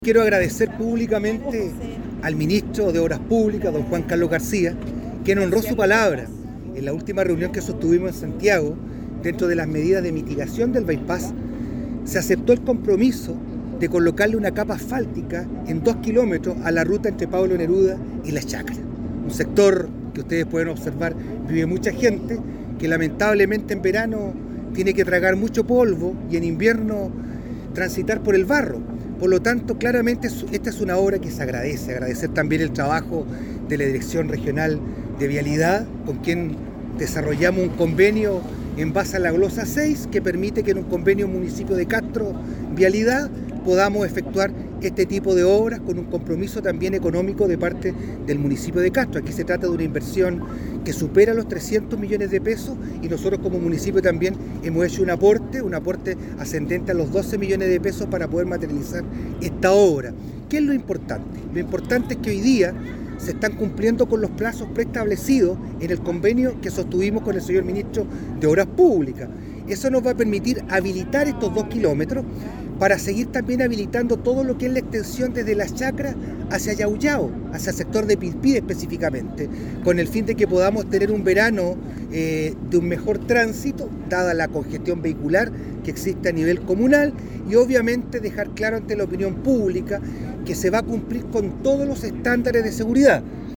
Lo anterior se desprende luego de la visita que realizaron a la obra diversas autoridades locales, provinciales y regionales, encabezadas por el Ministro de la cartera de Obras Públicas, Juan Carlos García.
En la ocasión, el alcalde Castro, Juan Eduardo Vera, agradeció el compromiso del jefe de la cartera de Obras Públicas: